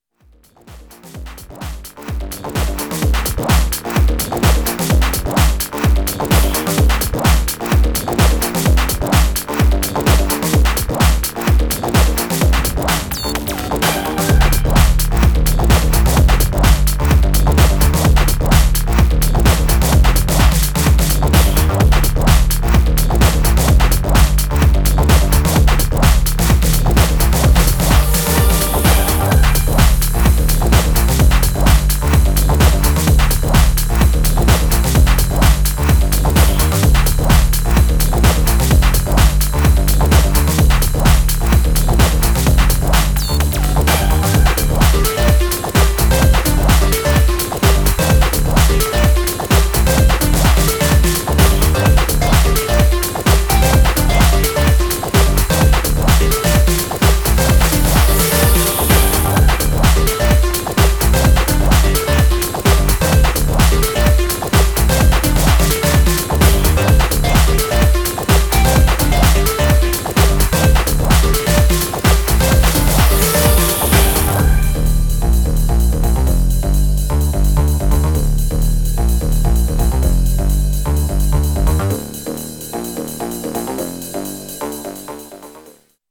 Styl: Progressive, House